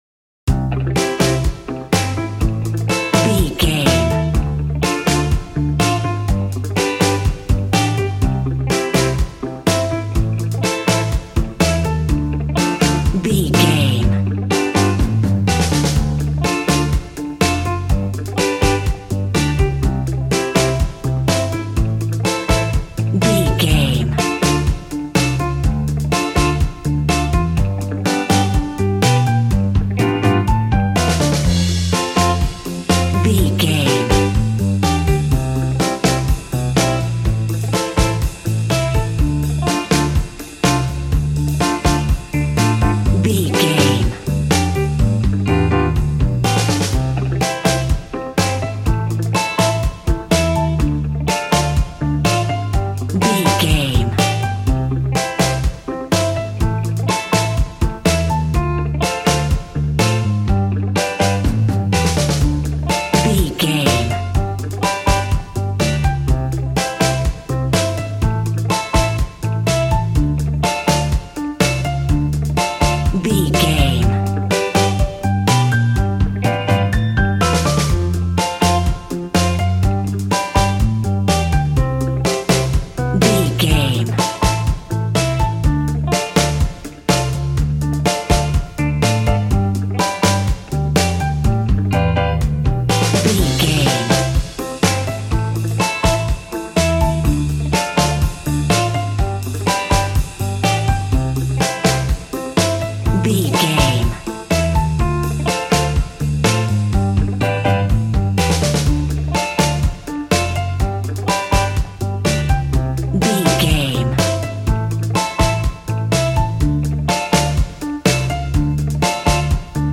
Ionian/Major
cheerful/happy
double bass
drums
piano
50s